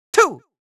countIn2.wav